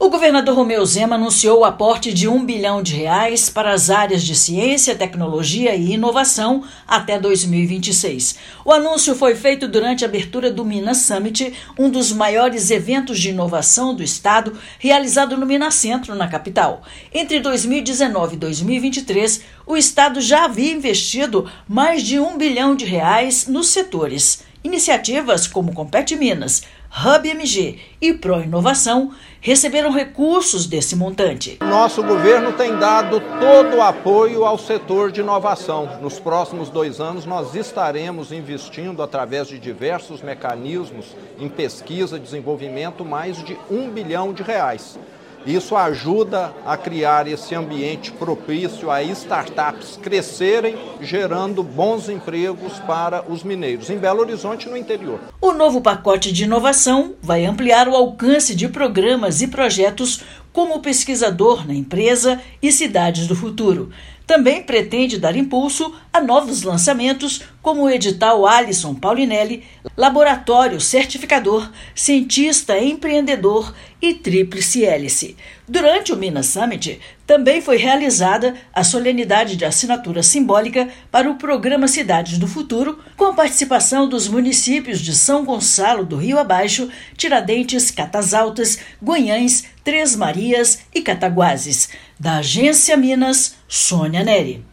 No Minas Summit, Estado detalhou como recurso vai ampliar alcance de programas como Compete Minas, HubMG GOV e Pró-Inovação. Ouça matéria de rádio.